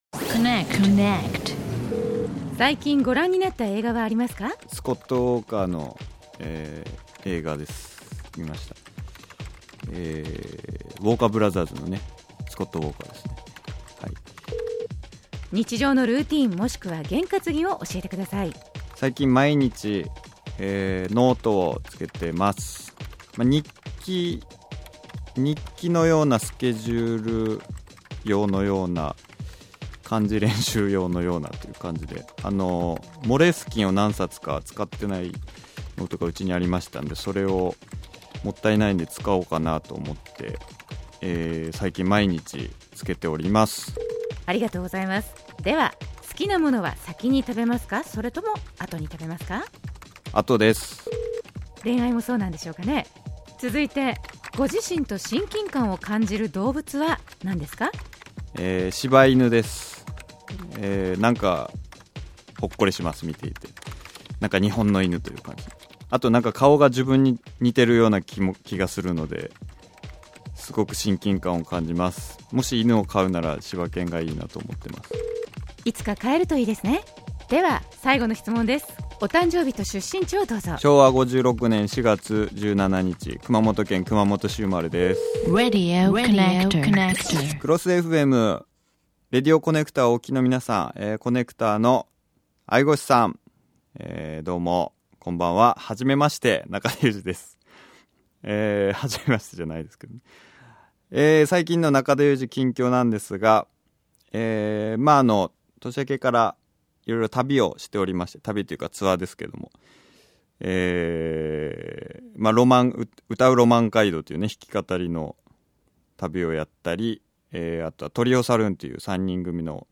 番組にコネクトして下さるゲストスタイル。